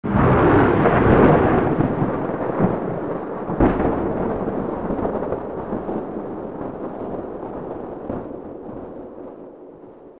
Root > sounds > weapons > hero > zuus
cloud_cast.mp3